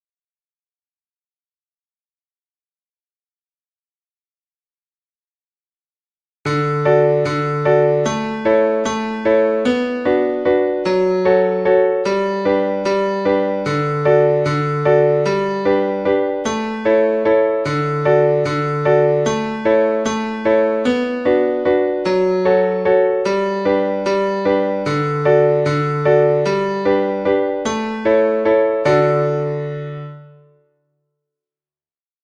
The first one starts with duple subdivision and finishes with triple and the second one starts with a triple subdivision and finishes with a duple one.
Duple_triple
binario_ternario.mp3